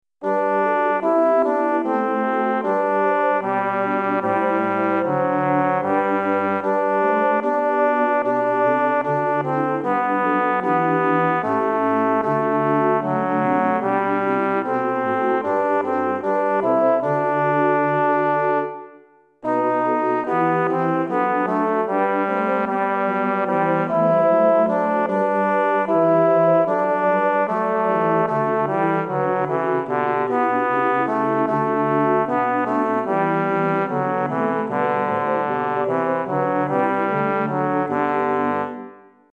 Nu vilar folk och länder tenor
nu vilar folk_tenor.mp3